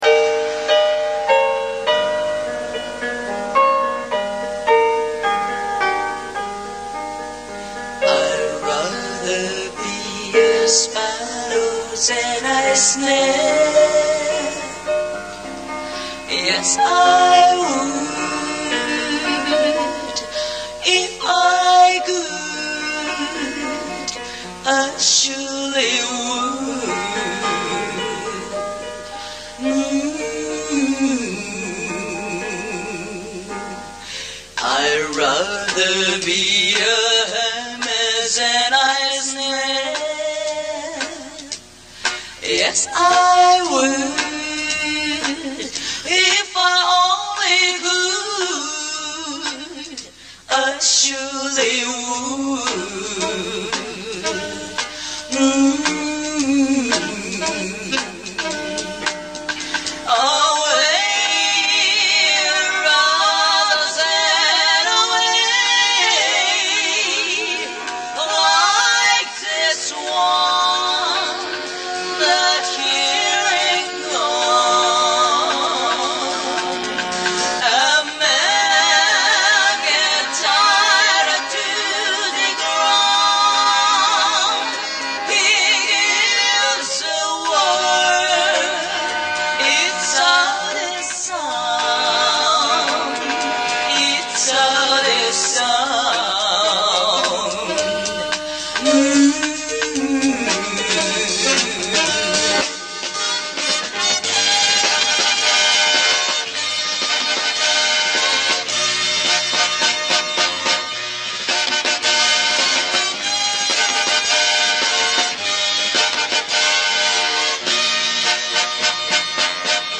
Ну какая у меня позиция....Качество аудио - да,ужасное...И правЫ будут модераторы,если удалят это....
А поют скверно .
К сожалению,качество записи очень низкое....Похоже,из зала на бытовой дешевый магнитофон записывали концерт....По такой записи вряд ли можно что определить,я понимаю...
Но тут вокал явно не японский, а английский (хотя поют, возможно, и японцы, - некий азиатский акцент прослушивается...)
К сожалению,речь японская....Нет возможности понять,как была объявлена эта песня перед началом исполнения....Единственно,что можно сказать-на японском это было сказано....